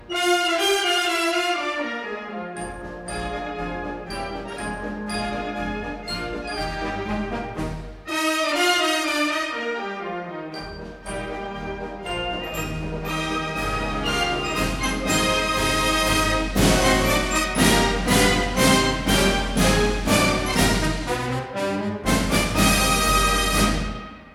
a 1958 stereo recording